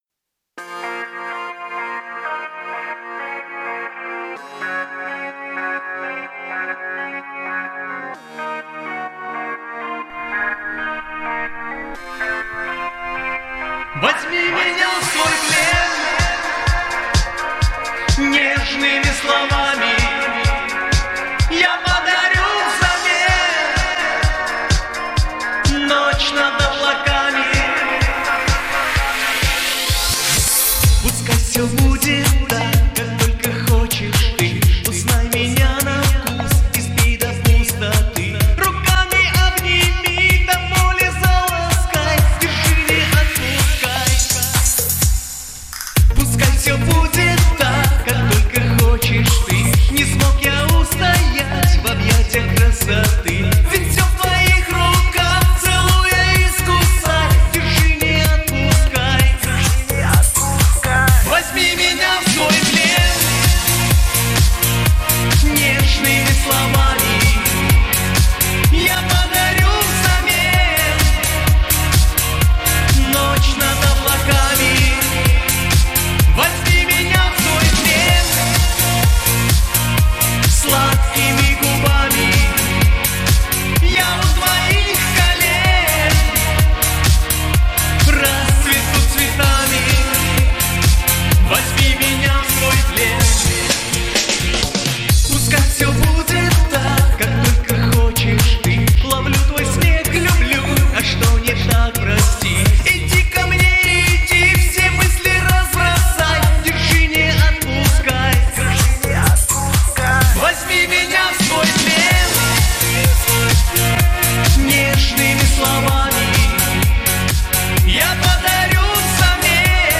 технично обработано, вот такое впечатление...v_naushnikah